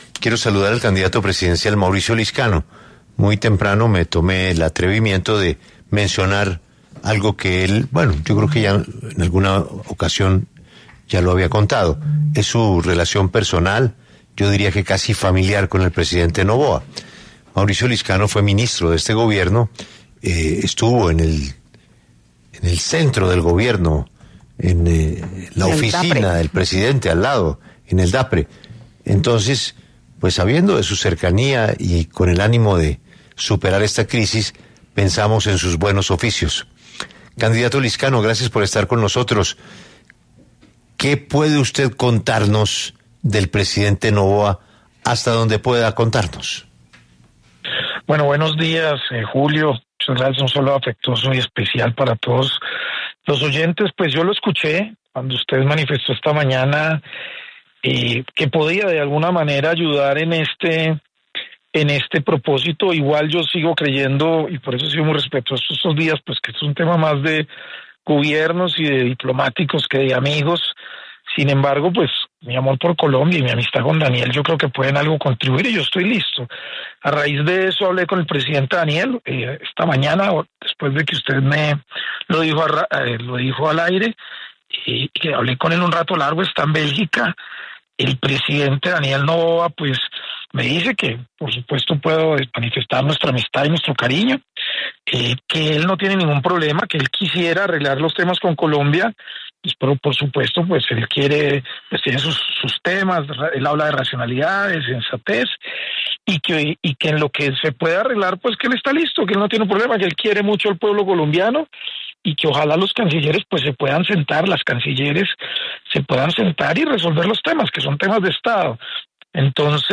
En diálogo con 6AM W, el candidato presidencial, Mauricio Lizcano explicó que sostuvo una conversación directa con el presidente de Ecuador, Daniel Noboa, en la que el mandatario ecuatoriano le reiteró que no existe ningún conflicto con Colombia y que su disposición es avanzar en la solución de los temas pendientes a través de los canales institucionales.